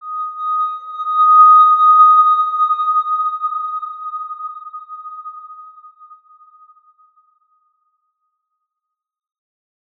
X_Windwistle-D#5-mf.wav